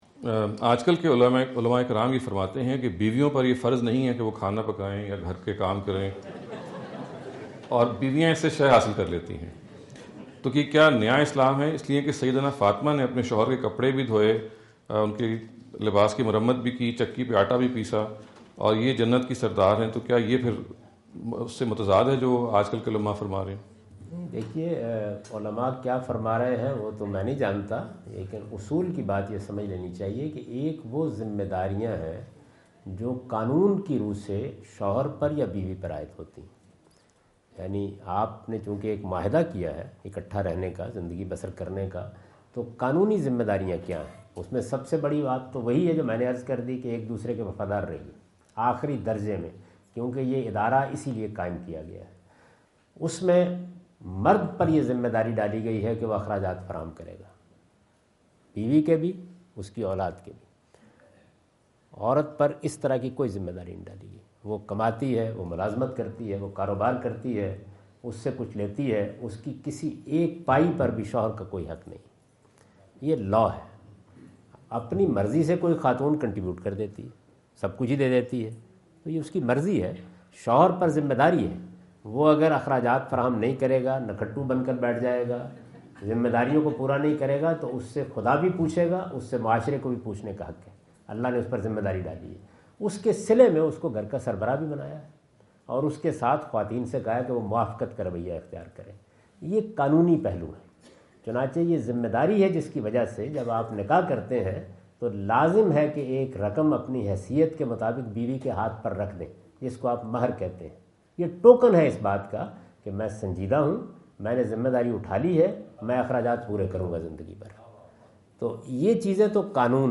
Javed Ahmad Ghamidi answer the question about "Responsibilities of Husband and Wife" asked at Corona (Los Angeles) on October 22,2017.
جاوید احمد غامدی اپنے دورہ امریکہ 2017 کے دوران کورونا (لاس اینجلس) میں "میاں بیوی کی ذمہ داریاں" سے متعلق ایک سوال کا جواب دے رہے ہیں۔